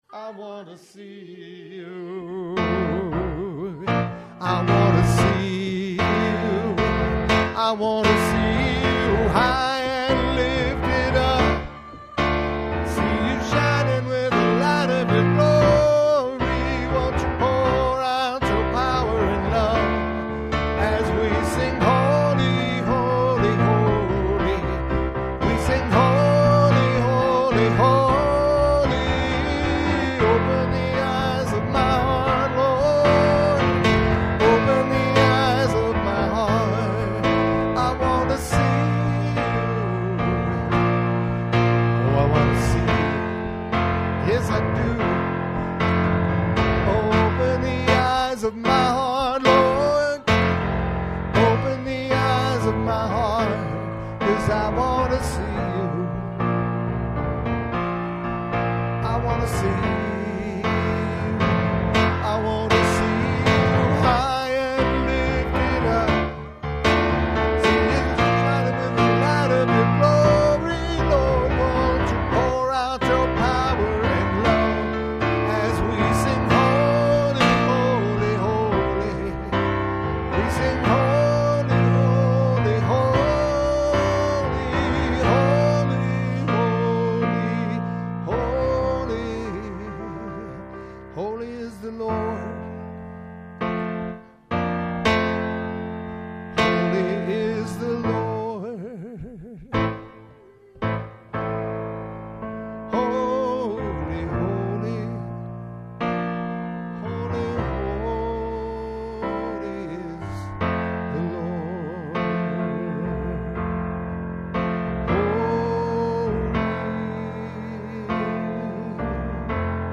WORSHIP 11 2.mp3